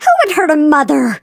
flea_hurt_vo_05.ogg